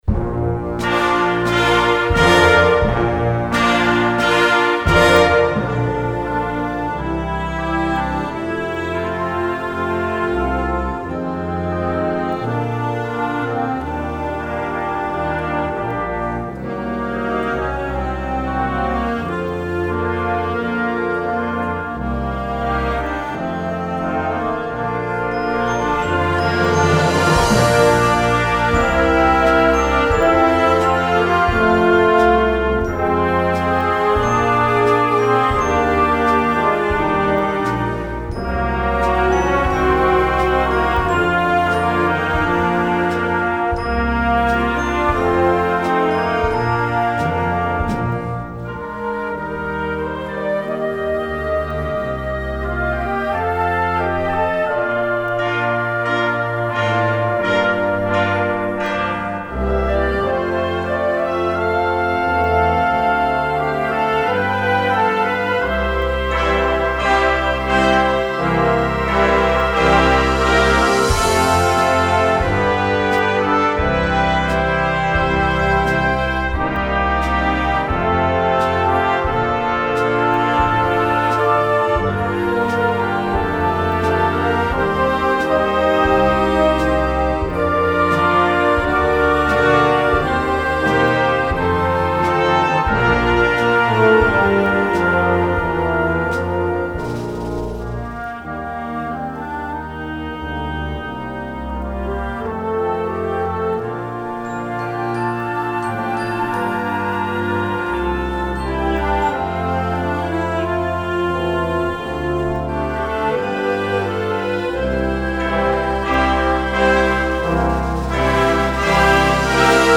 easy to learn while sounding rich and impressive